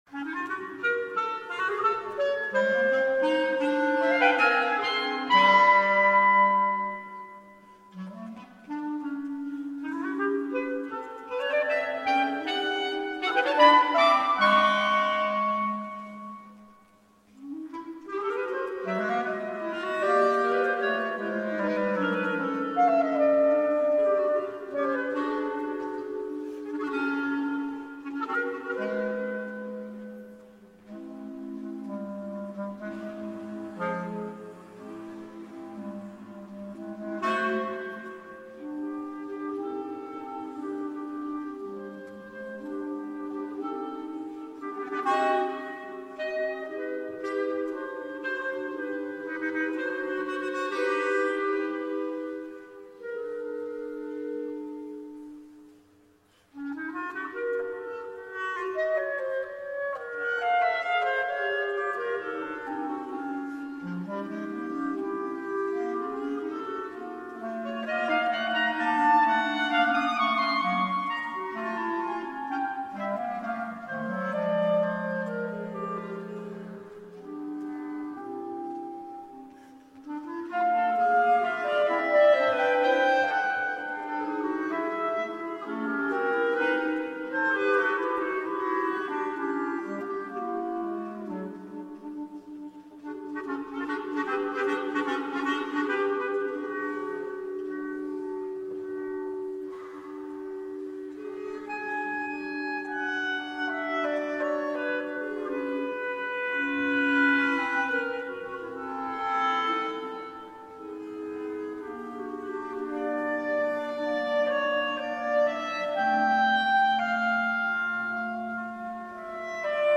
for clarinet trio
(per tre clarinetti in sib)